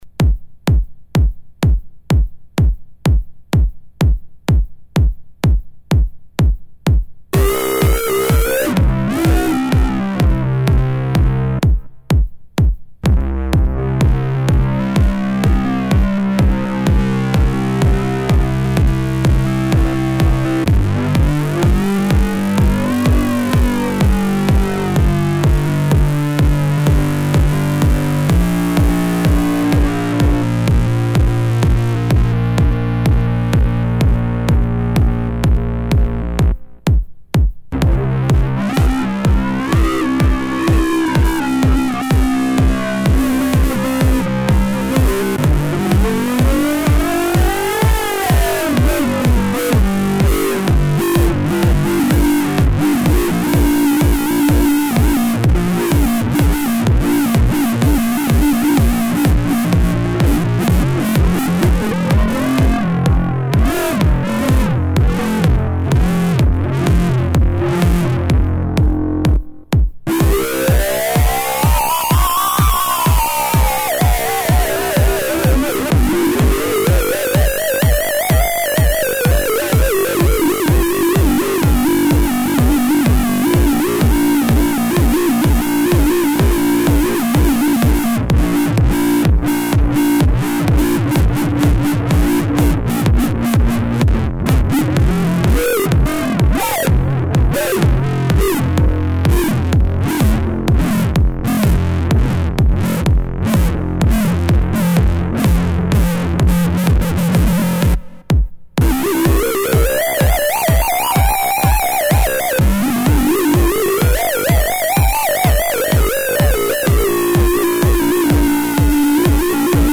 Hommage an wilde Synth Riffs.. - oder was man alles mit Filtertechnik zaubern kann. Stars dabei sind : der Jupiter XM und Korg Kronos samt eingebautem Karma Joystick.